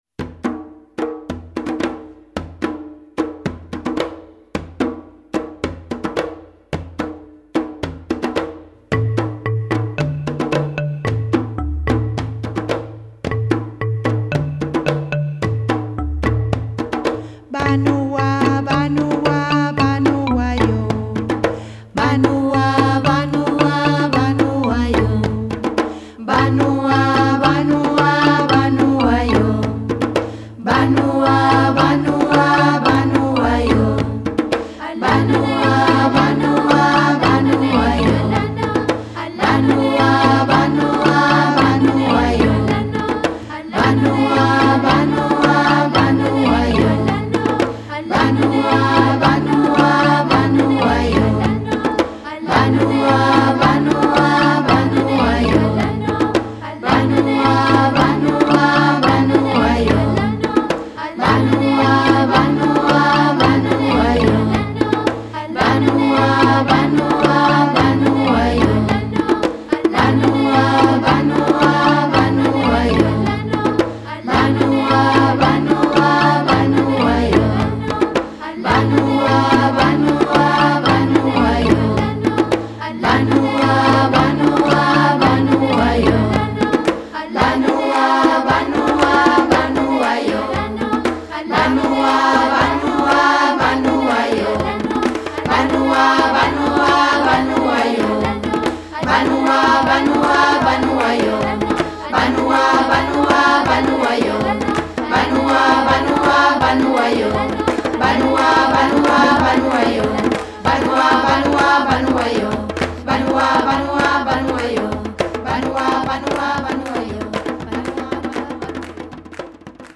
Canción de origen africana (Liberia) para acompañar con ostinatos melódicos, instrumentales y/o vocales.
Instrumentos como claves, tambores y maracas son adecuados para que los más pequeños acompañen esta canción.
Los contenidos musicales para trabajar son: pulso, ostinato y agógica.